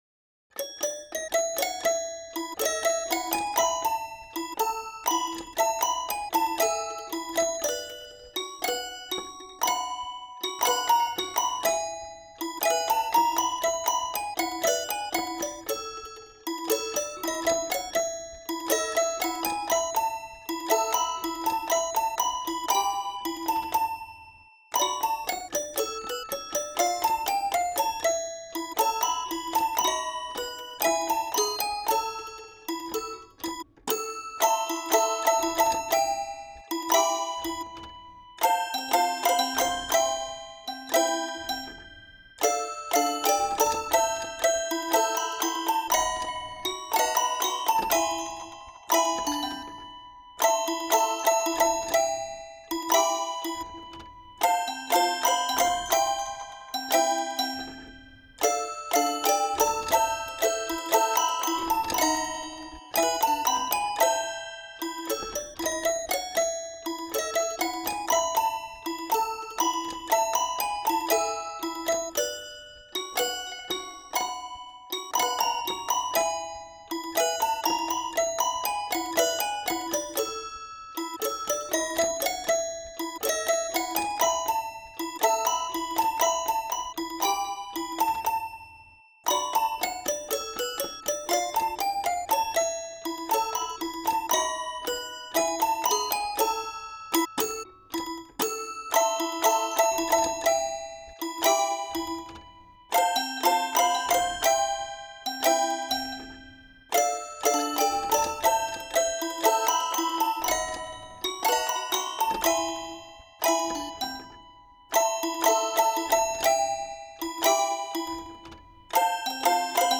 This is a sampled toy piano from the Jaymar company that originally featured Disney characters on the face.
Notes range from C2 to C4.
Recorded with Oktava MC012 stereo pair microphones plugged into Focusrite ISA preamps. 24/48 RME AD converters.